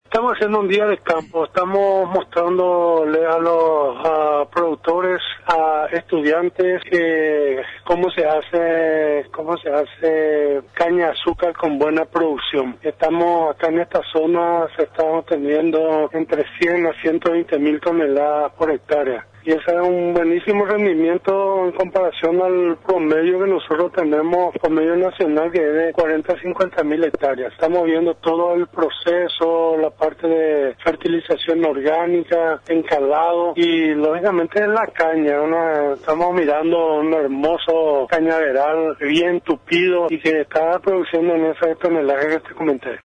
Con la finalidad de obtener una producción de unas 120.000 toneladas por hectárea de caña de azúcar, el Ministerio de Agricultura y Ganadería (MAG) brinda asistencia técnica a productores de la localidad de Arroyos y Esteros, resaltó el viceministro Nicasio Romero.